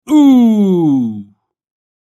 Male-oof-sound-longer.mp3